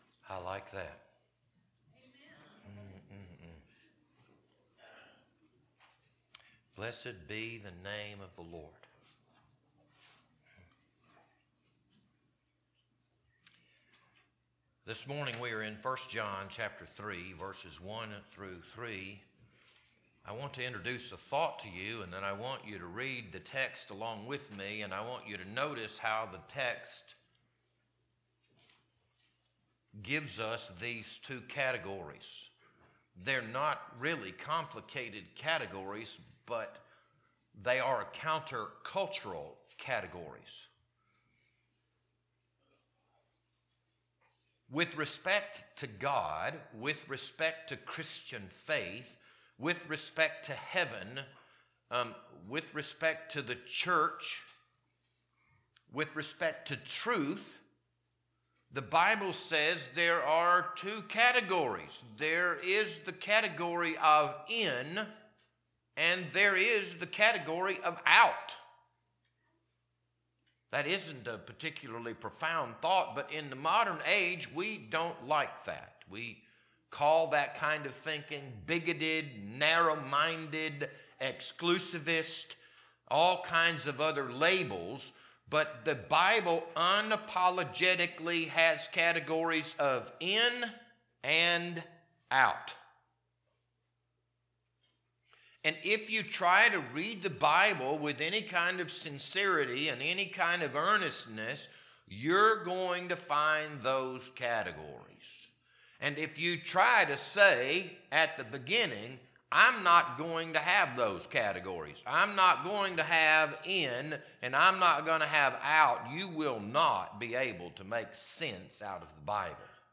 This Sunday morning sermon was recorded on March 29th, 2026.